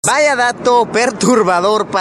Tono para tu móvil de VAYA DATO PERTURBADOR
Una de las famosas frases del Youtuber Luisito Comunica